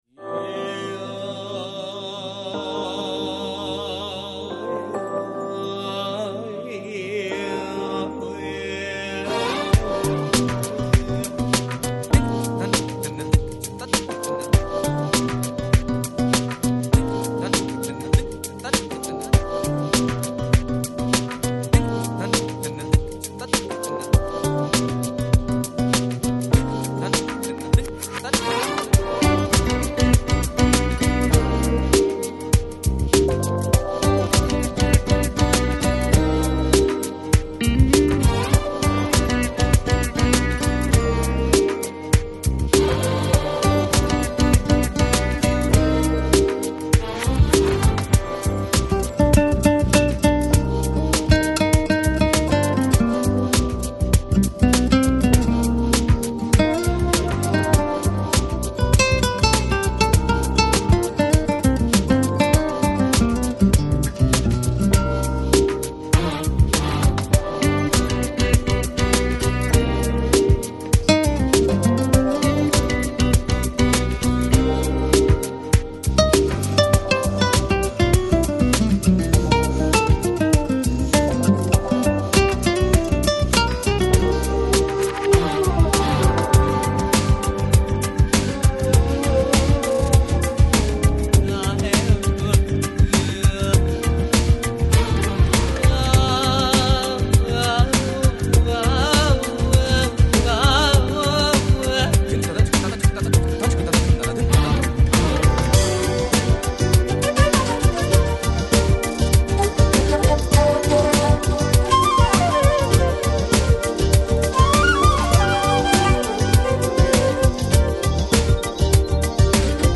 Жанр: Jazz, Smooth Jazz, Lounge